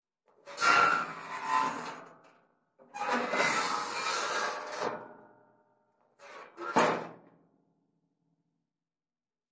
弹簧式金属门吱吱嘎嘎地打开关闭 02
描述：弹簧加载的金属门 打开和关闭，吱吱作响，生锈和吱吱作响。
Tag: 加载 生锈 吱吱 吱吱 生锈 铰链 金属 发出刺耳的声音 摇摇欲坠 弹簧 闭门 呻吟 尖叫 铮铮 处理